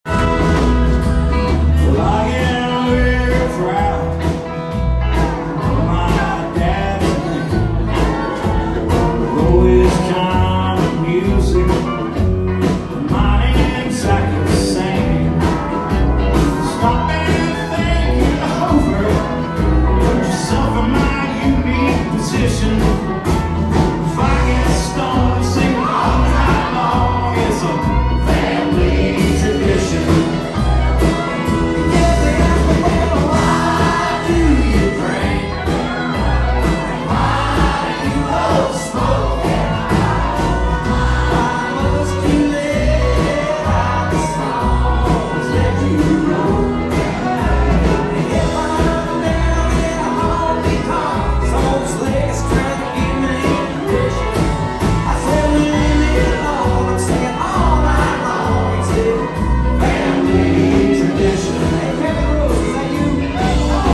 Hank Lives! Savannah musicians bring country legend's spirit to Victory North – Savannah Agenda
I love great music performed live by great musicians and Saturday night’s